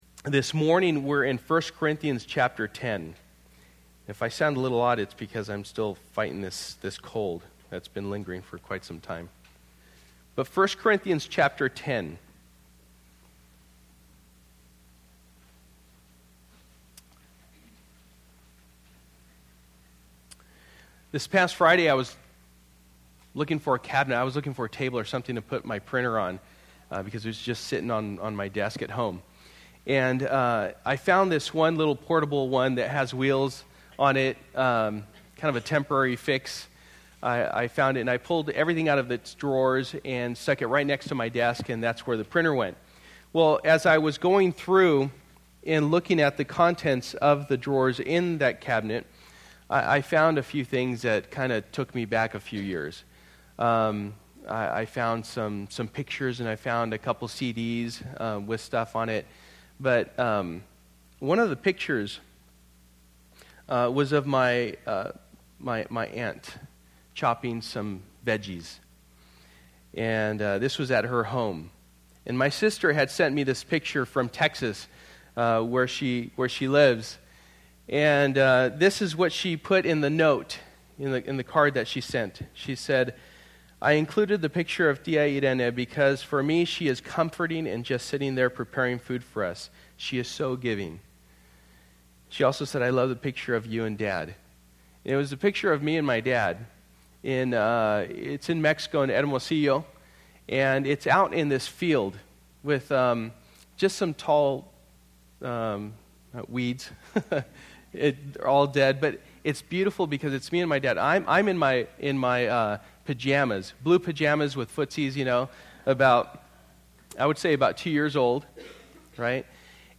1 Corinthians 10:1-31 Service: Sunday Morning %todo_render% « Nehemiah 9 Sold Out